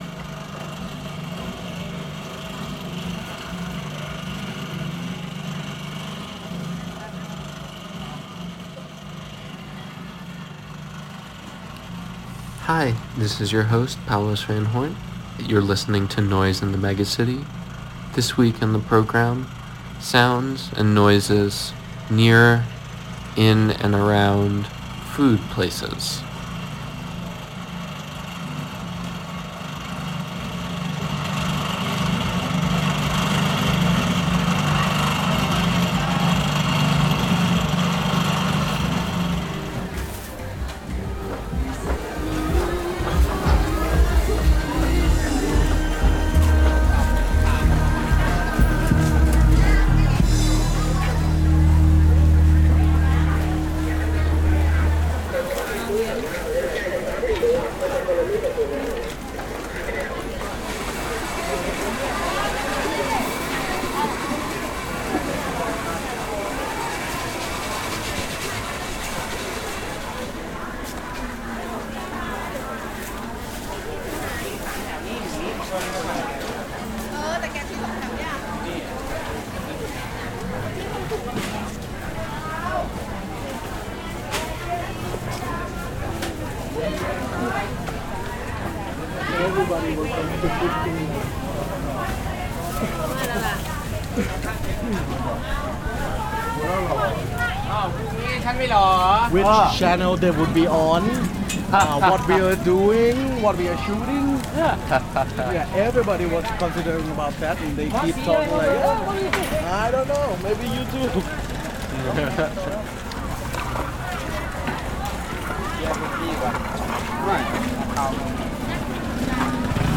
A dive into an archive of collected noises from 10 megacities across the globe. Interviews and ruminations will be mixed in with these sounds, in search of a better understanding of a fundamental question: "what is noise?